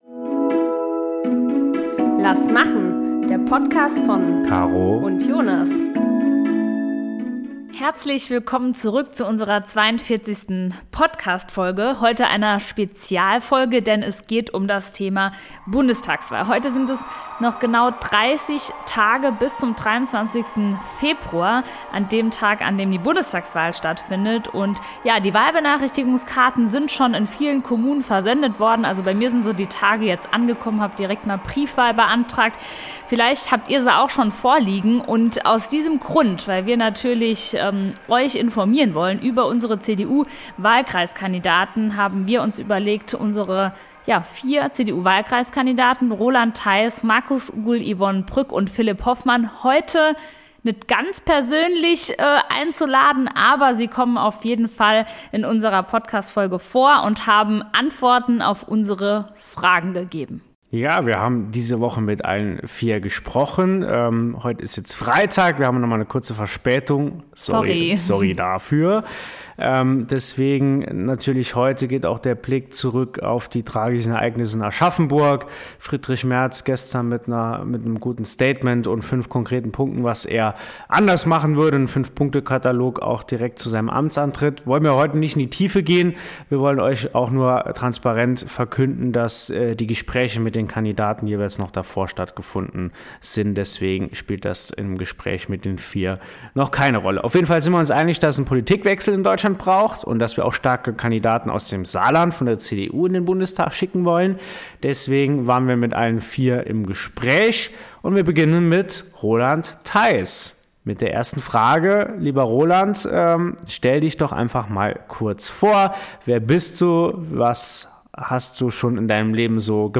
"Lass machen" in Folge 42 direkt mit vier Gästen